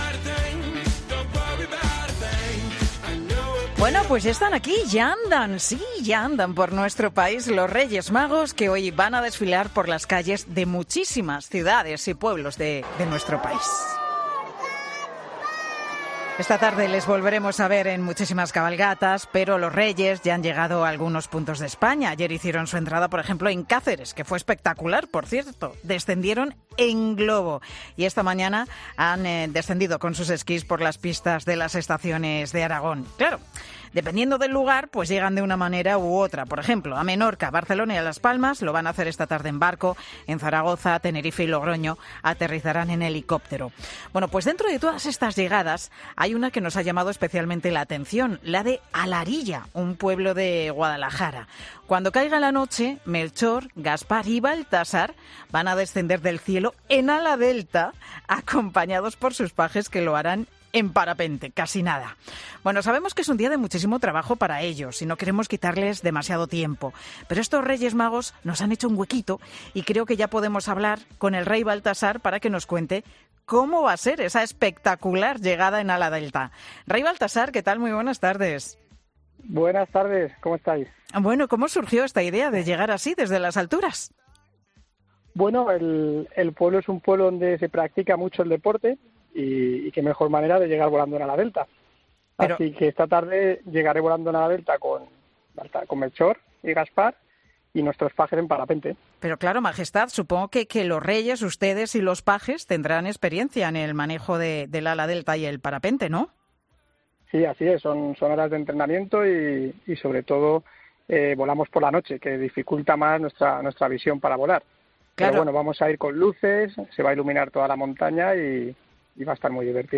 Como consecuencia, el rey Baltasar ha sido entrevistado en 'Mediodía COPE'.